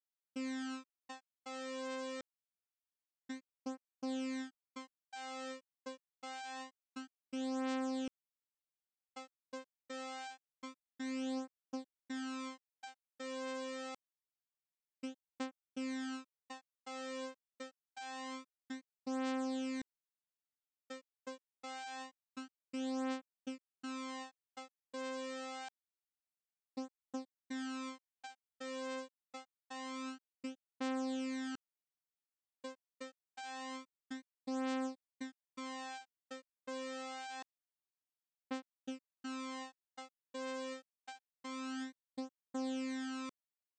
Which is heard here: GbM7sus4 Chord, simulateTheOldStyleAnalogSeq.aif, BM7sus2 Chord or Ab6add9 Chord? simulateTheOldStyleAnalogSeq.aif